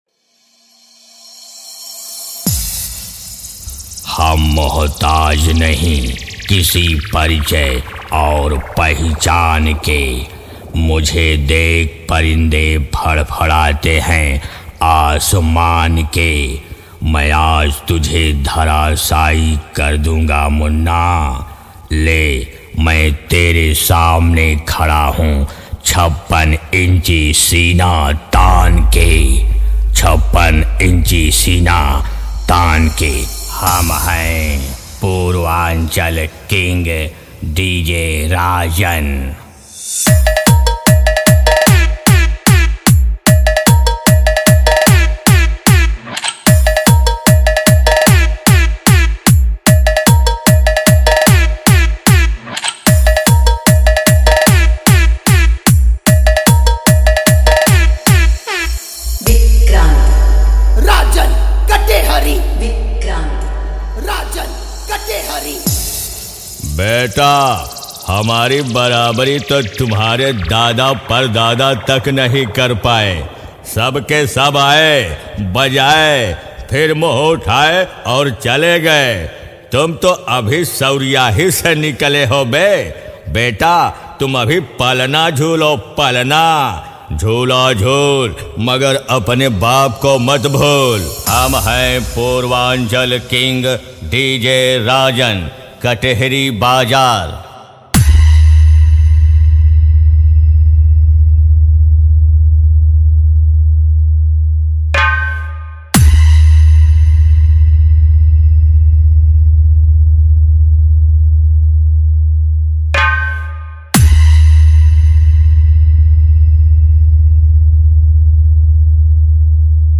DJ Competition Songs